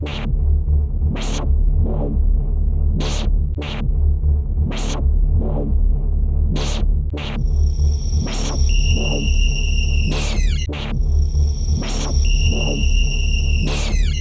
31 Futurefunk-a.wav